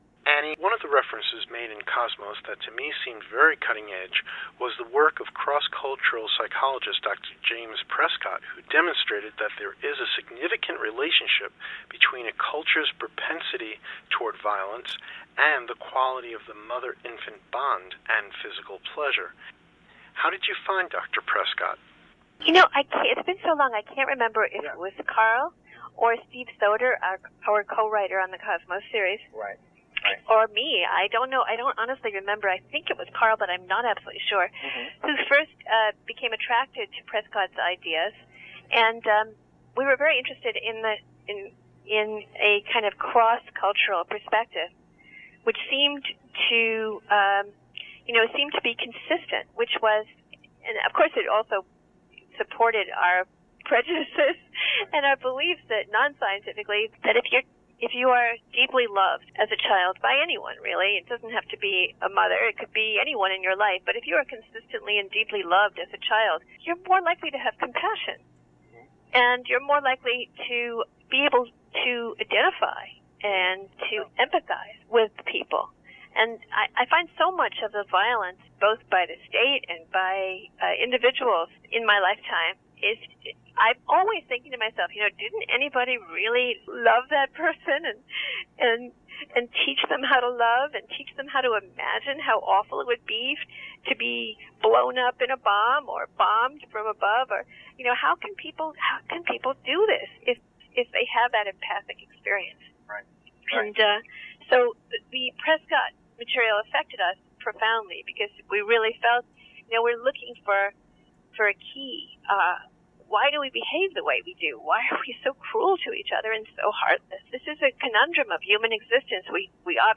An audio clip (2 minutes, 20 seconds) from the interview is available for download in WAV and Ogg Vorbis formats.